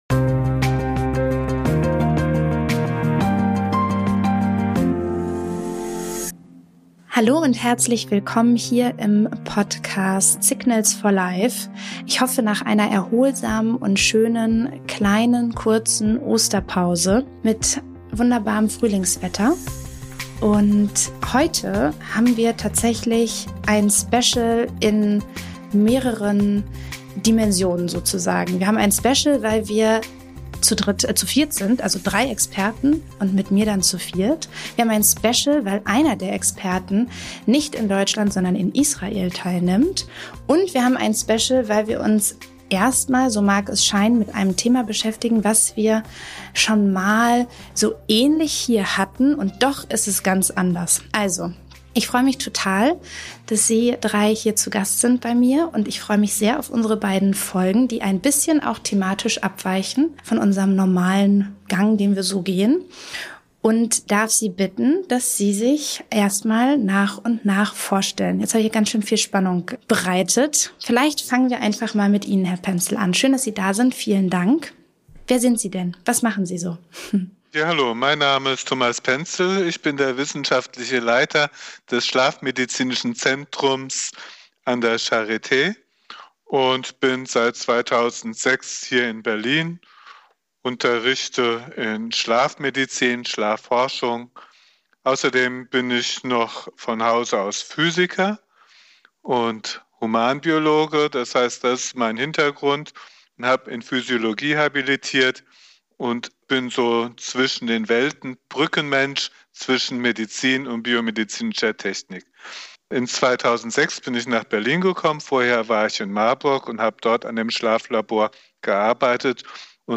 Beschreibung vor 3 Jahren Mehr Specials kann eine Folge wohl nicht haben: eine Doppelfolge mit drei Experten, ein Expertenteam aus Deutschland und Israel und wir beschäftigen uns wie in unseren ersten beiden Folgen mal wieder mit Schlaf.